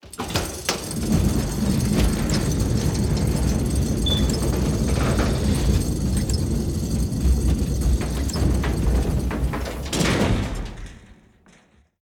Portcullis Gate.ogg